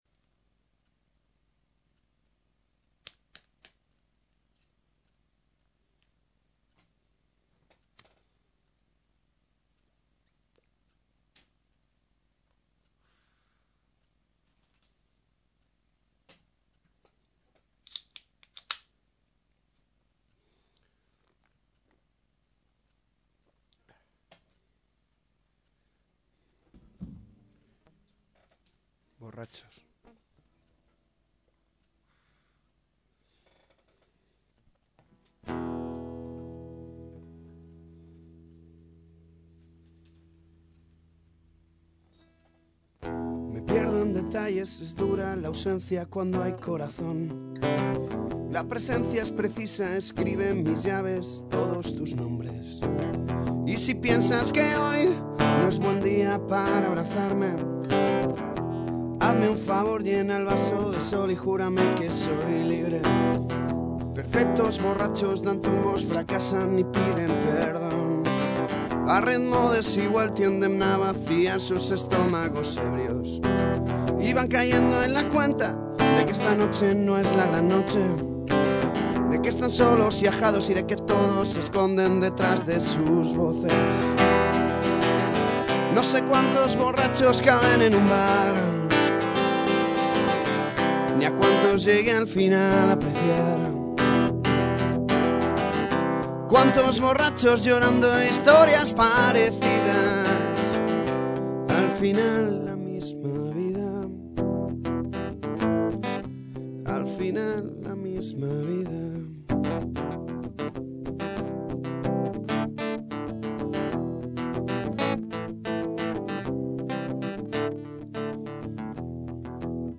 Aún me falta una estrofa, pero irá así:
(Rueda: mi, la 7ª, SI 7º, DO,
Estribillo: DO, SI 7ª, la 7ª,